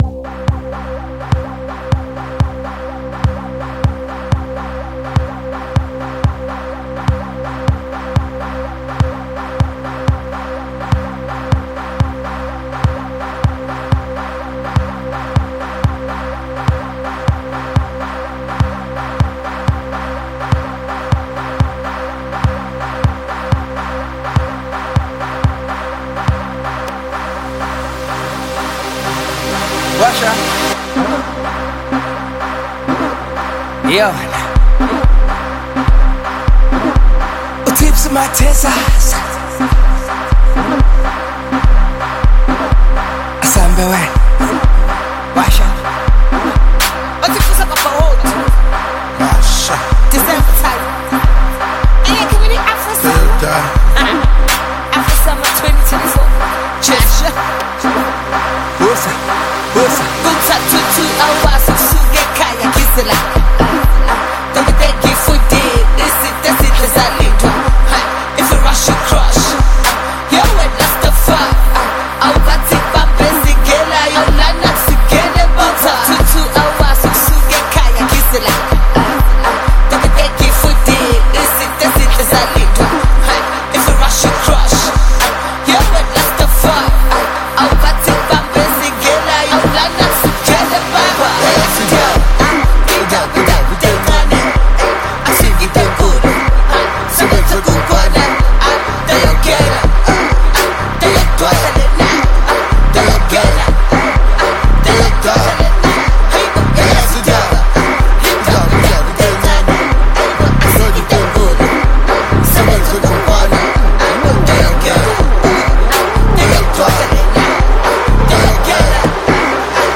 • Genre: Electronic